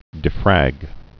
(dĭ-frăg)